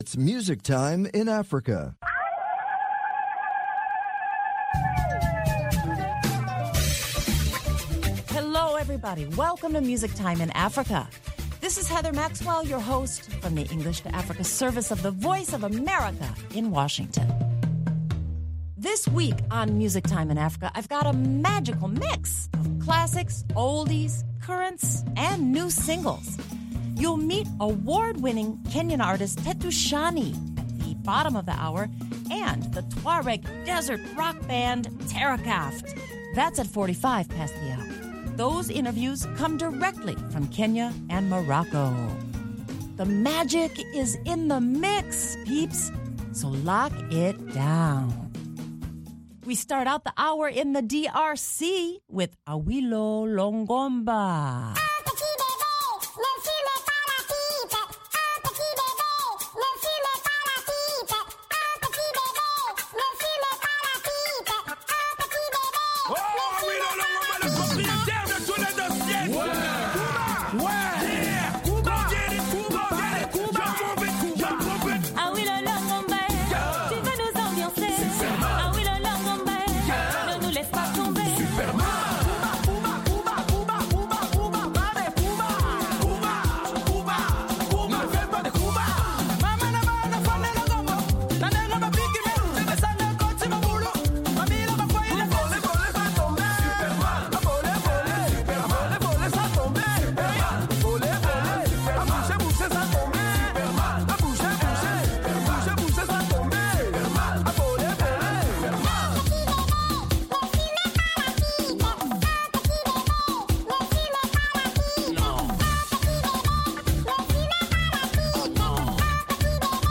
Terakaft live acoustic - Music Time in Africa
Listen to them play live and unplugged from the International Nomads Festival in M'Hamid el Ghizlane.